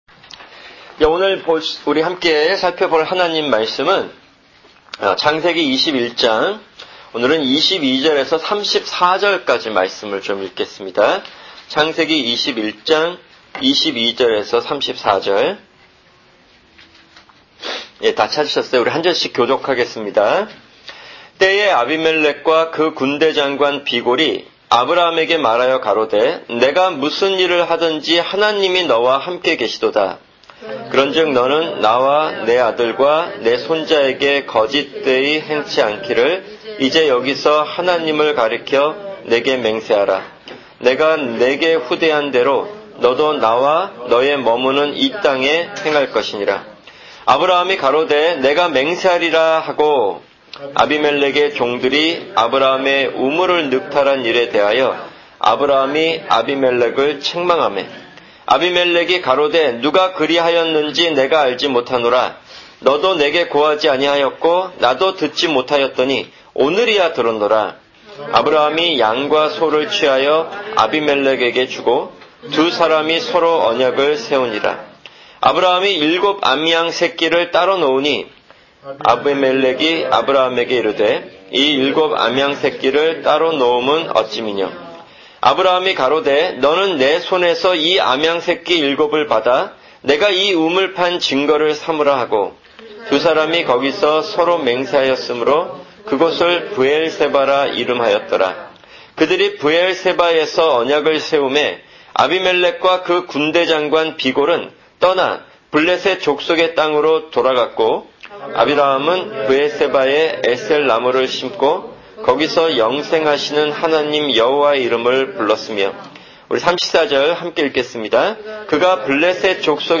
[주일설교] 에스겔(64) 44:1-3